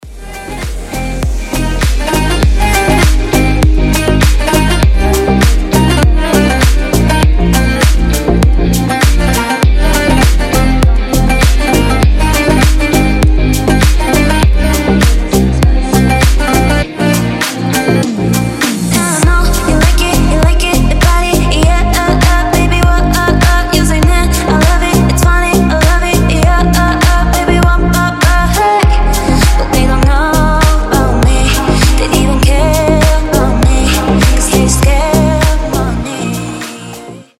• Качество: 320, Stereo
гитара
deep house
мелодичные
женский голос
восточные
Кайфовая танцевальная музыка в стиле deep house